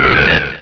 sovereignx/sound/direct_sound_samples/cries/nosepass.aif at master